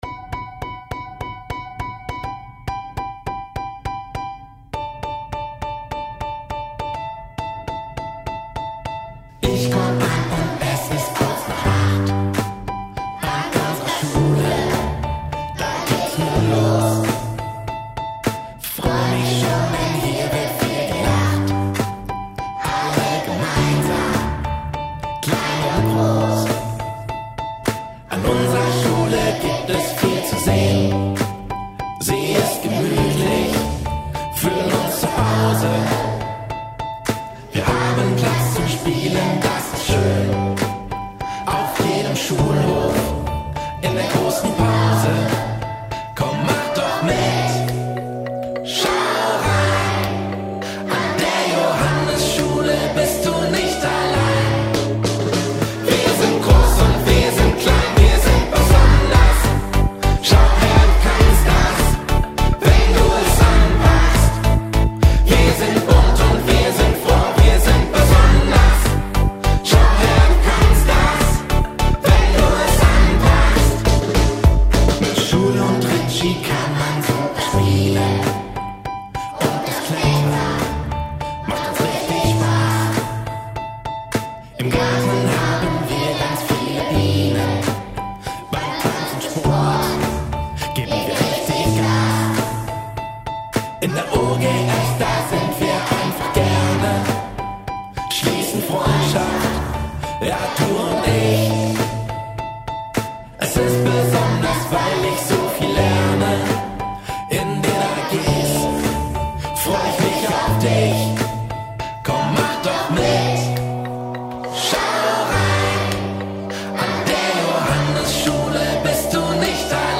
Das ist unser Schulsong!
SONG